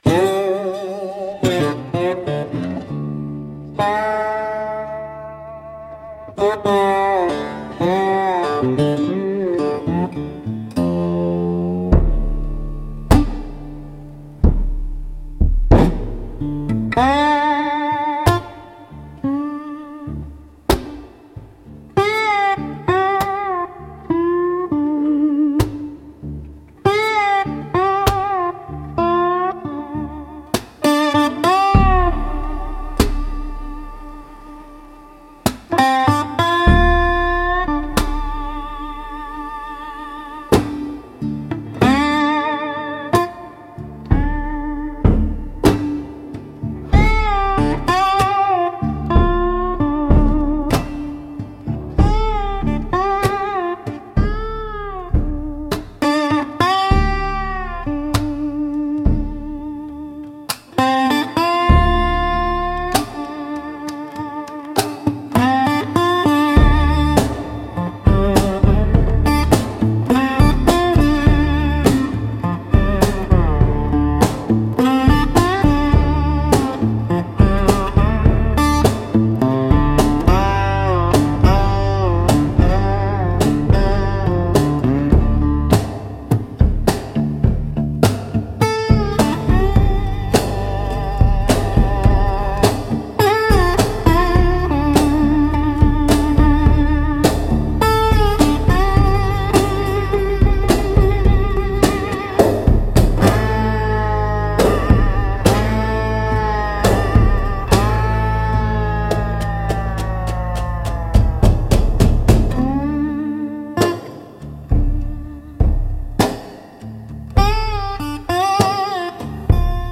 Instrumental -Blood on the Snare 4.41